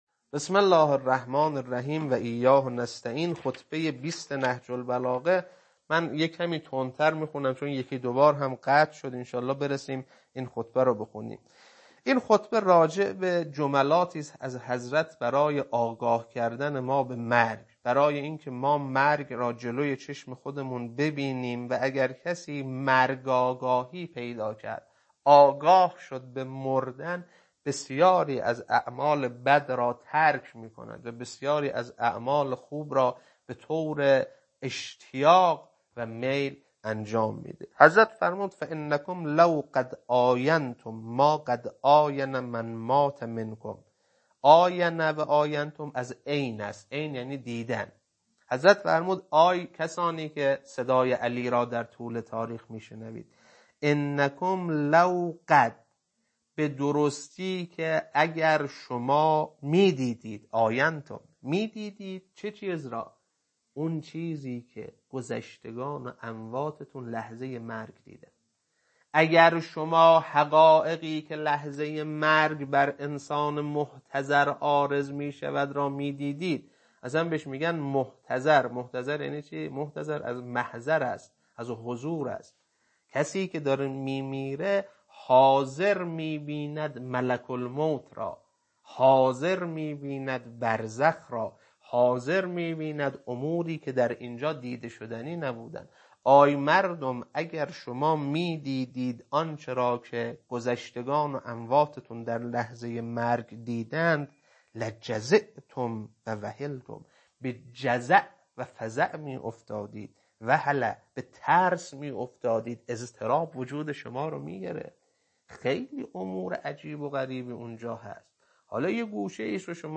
خطبه-20.mp3